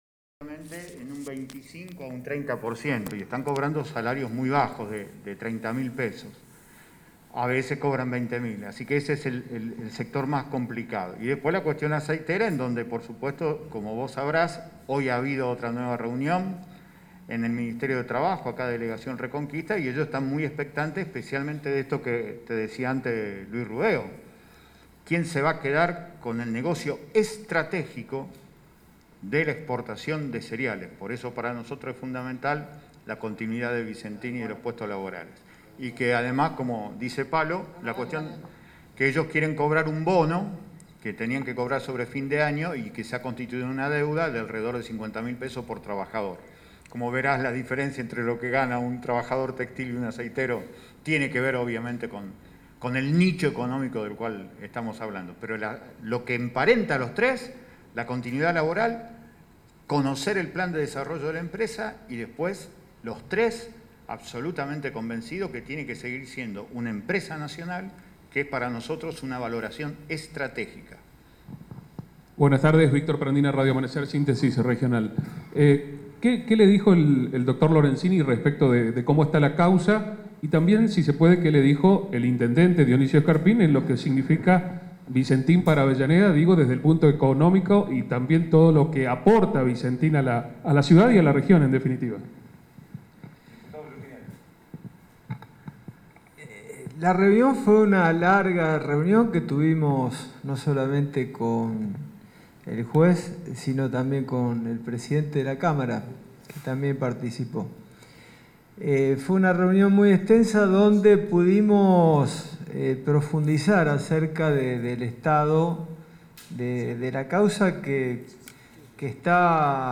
Tras las reuniones que se realizaron durante el mediodía y la tarde del miércoles, los legisladores brindaron una conferencia de prensa para comentar los detalles de los encuentros.
conferencia-comisión-vicentin-1.mp3